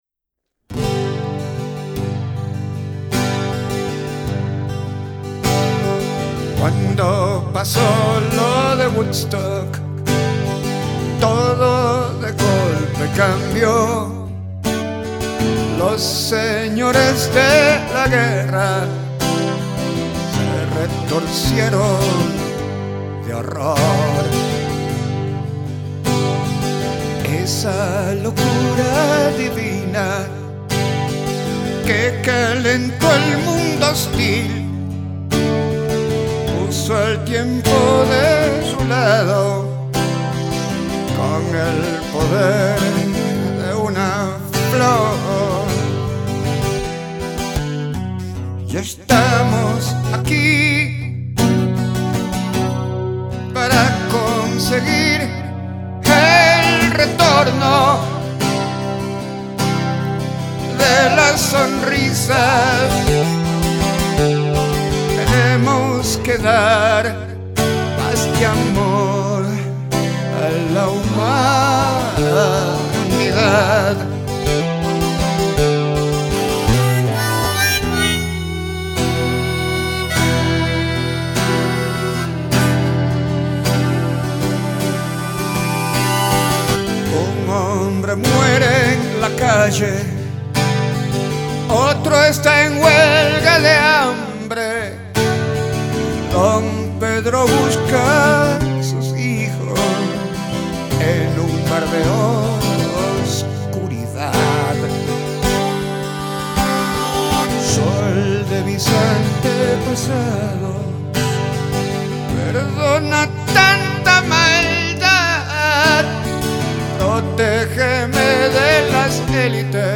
guitarra, voz.
violín, coro.